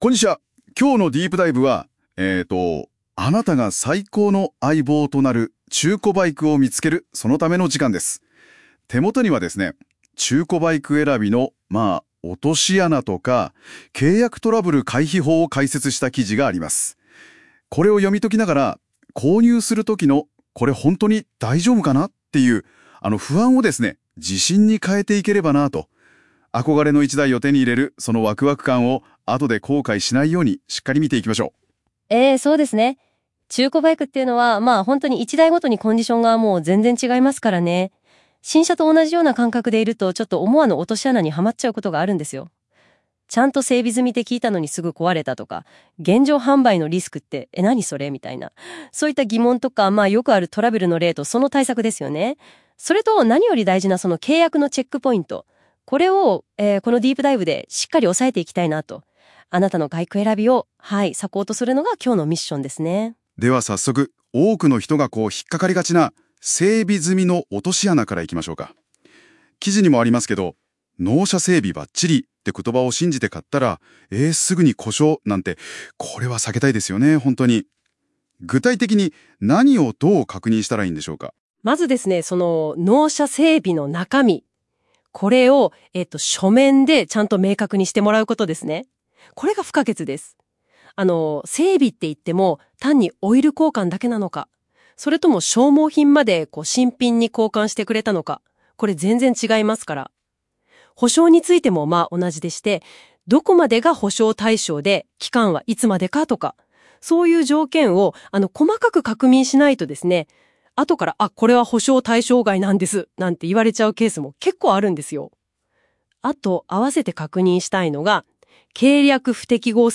記事に関連した内容をAIで音声制作しました。※漢字の読み違いなどがありますがご了承ください。